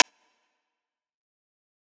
Metro Mini Perc.wav